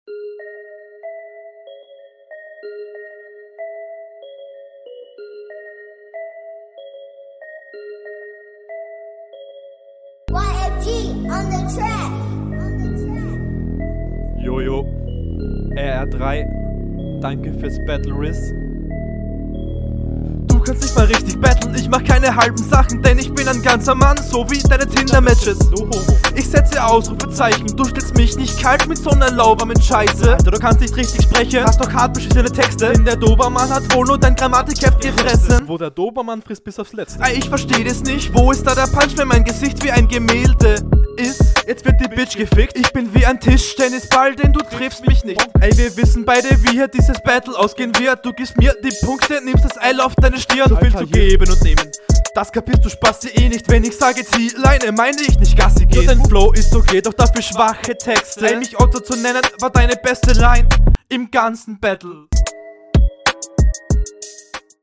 Du bist zwar klar aufn beat aber flowlich ist der Gegner überlegen, kontern kannst du …
Ja wie erwartet das Soundbild wie in den anderen Runden.